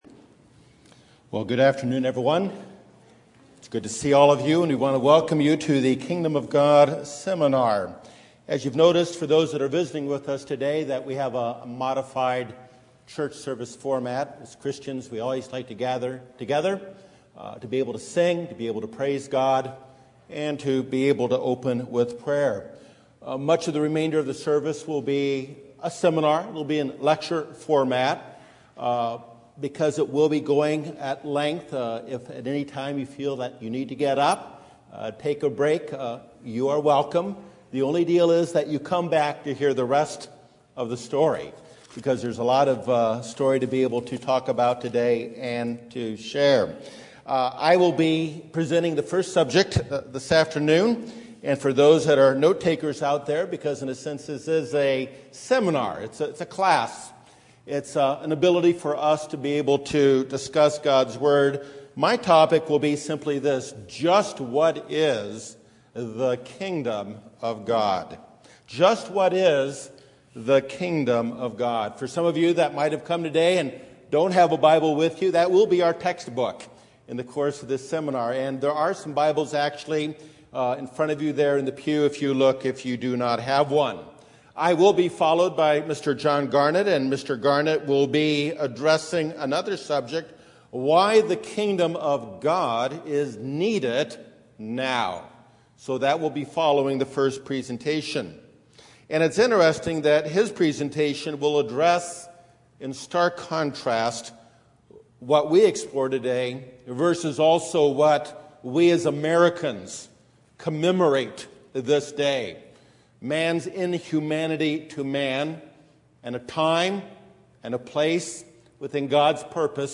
The first, in a series of six messages, about the Kingdom of God. This message presents a Biblically-based exposition of what comprises that Kingdom.